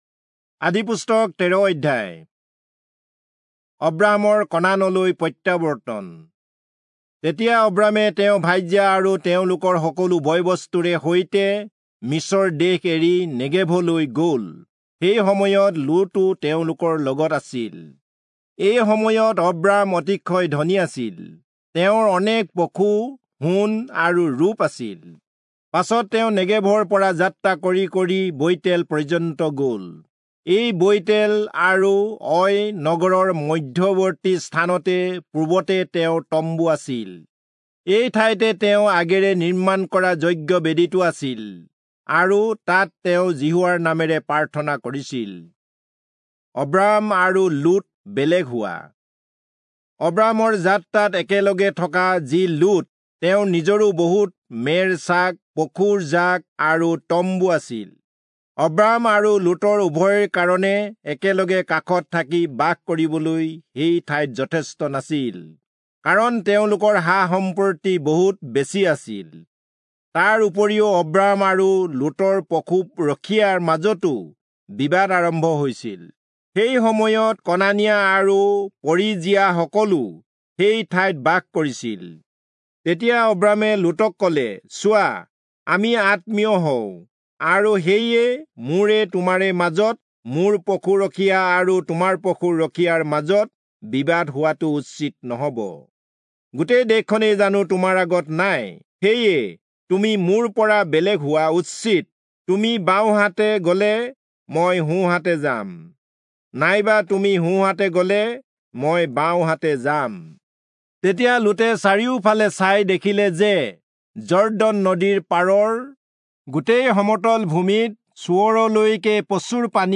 Assamese Audio Bible - Genesis 1 in Irvbn bible version